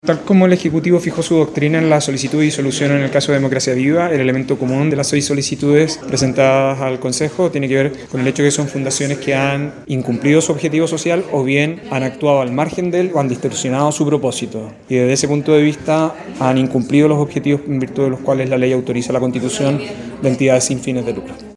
El ministro de Justicia, Luis Cordero, confirmó que pidió el cierre definitivo de dichas fundaciones.